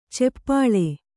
♪ ceppāḷe